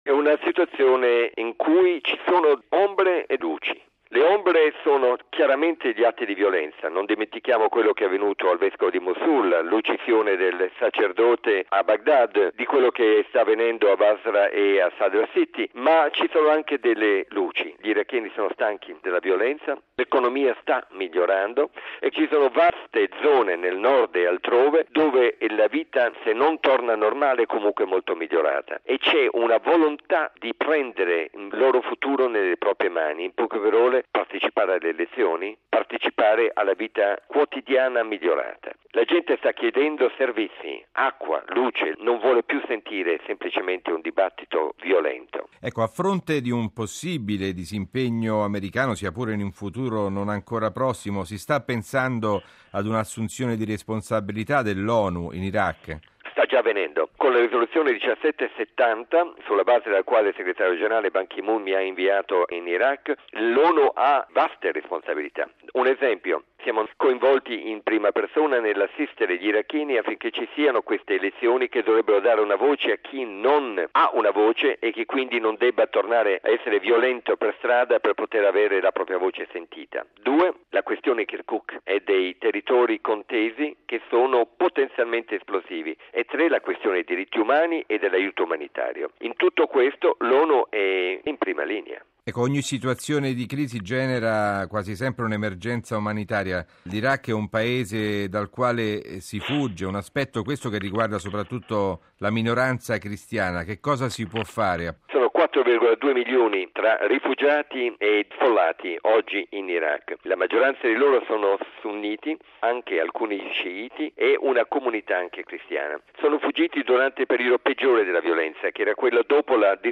Riportare la pace in Iraq, impegno primario dell’ONU. Intervista con Staffan de Mistura